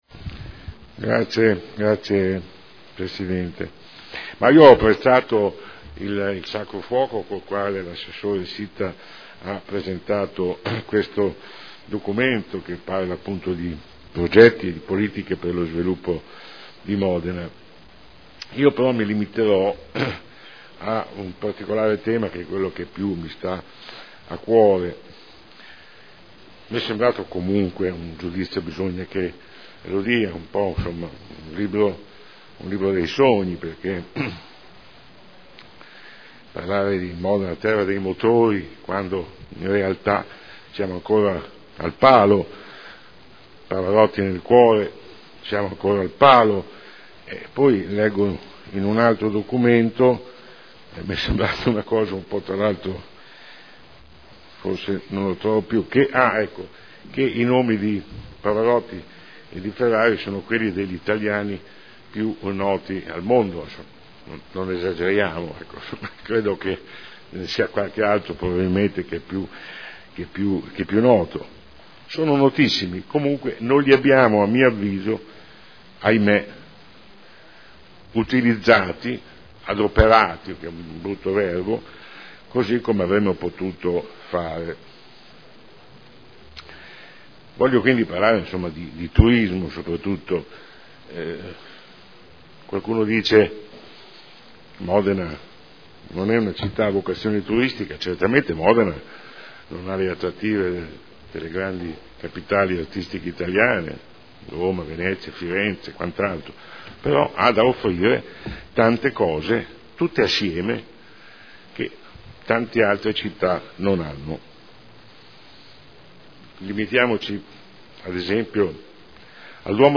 Sandro Bellei — Sito Audio Consiglio Comunale
Seduta del 28/01/2013 Dibattito. Politiche e progetti per lo sviluppo di Modena: approvazione linee di indirizzo